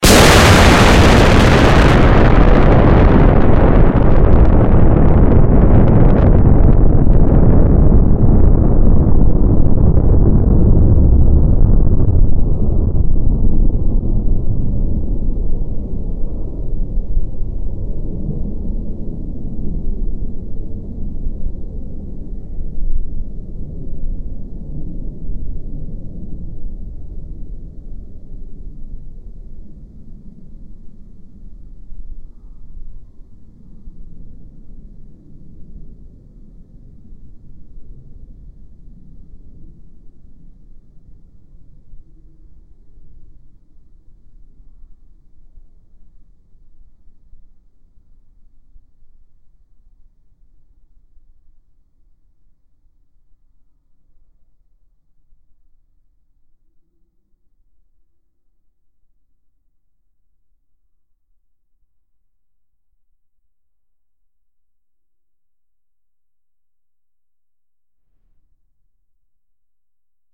nuclearExplosion.ogg